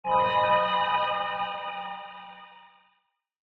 Minecraft Version Minecraft Version latest Latest Release | Latest Snapshot latest / assets / minecraft / sounds / ambient / cave / cave1.ogg Compare With Compare With Latest Release | Latest Snapshot
cave1.ogg